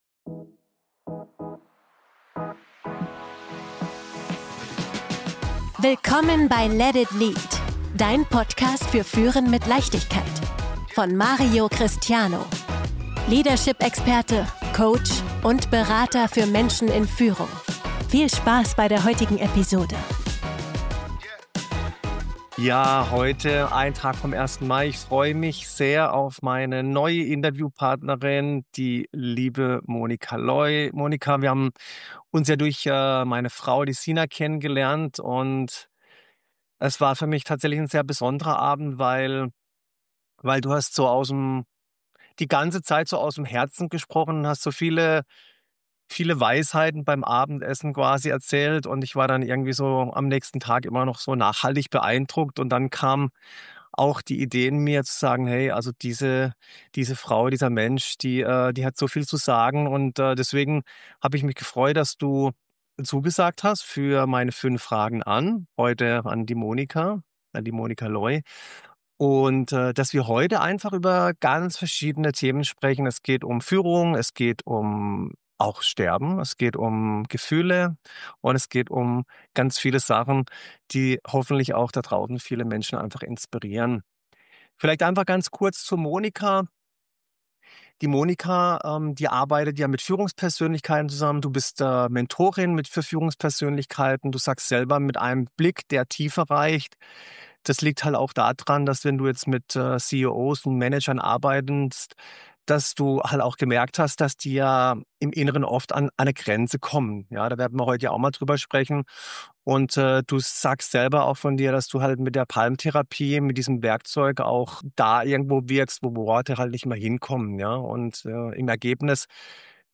Ein Gespräch über innere Stärke, Verletzlichkeit – und die Kraft, Frieden mit sich selbst zu schließen.